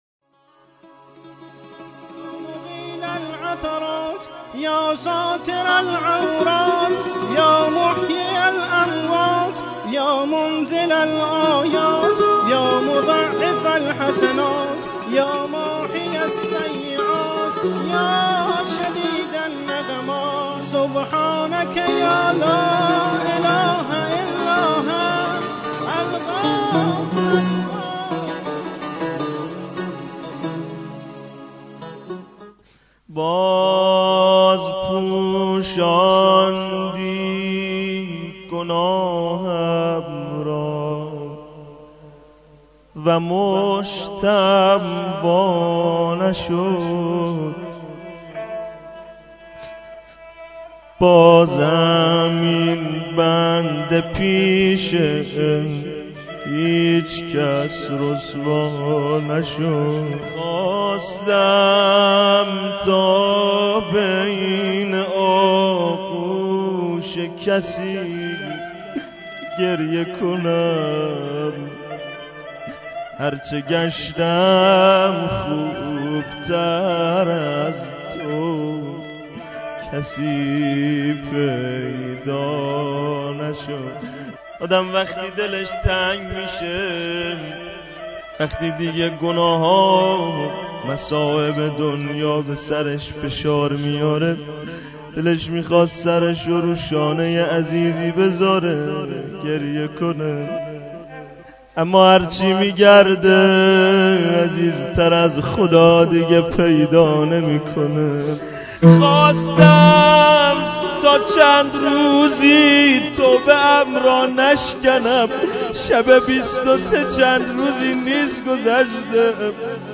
• اجراشده در هیات محبین انصارالمهدی عج بندرامام خمینی
• مناجات, نوای هیات